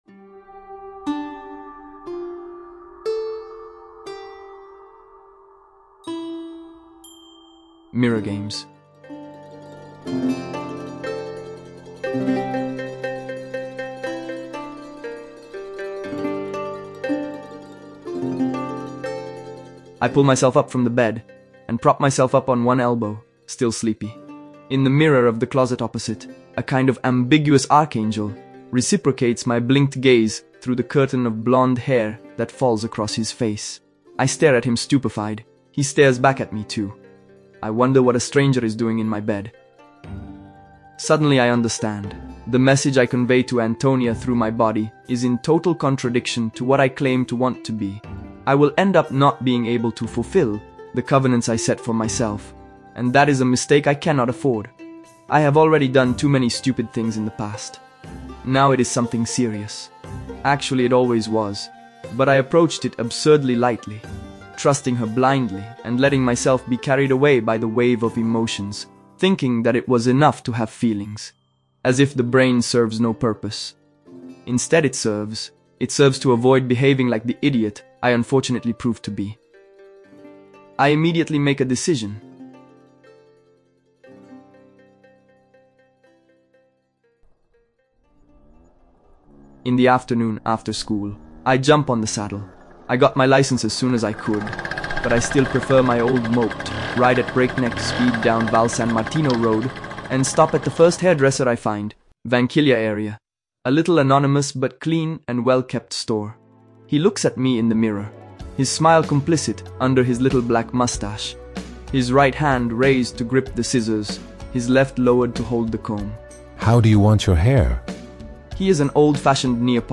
Podcast Novel